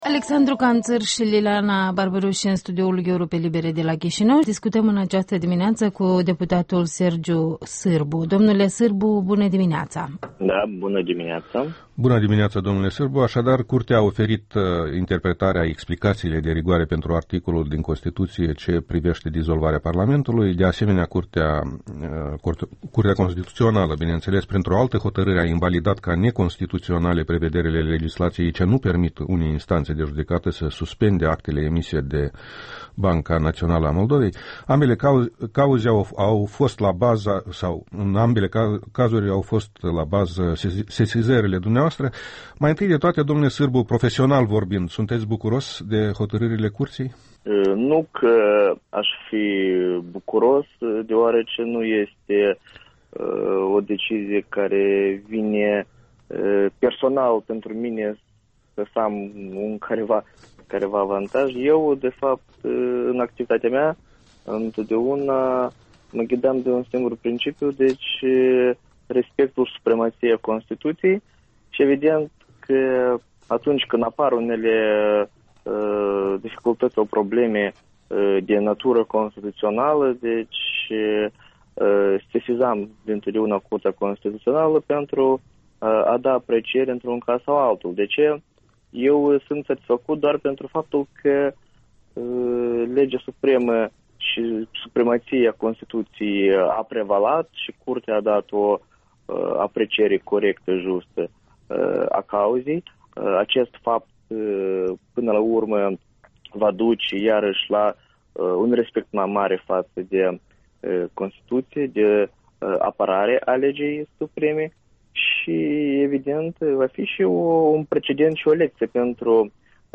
Interviul dimineții: cu deputatul Sergiu Sîrbu